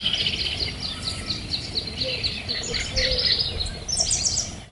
Little Thornbird (Phacellodomus sibilatrix)
Country: Argentina
Province / Department: Entre Ríos
Condition: Wild
Certainty: Recorded vocal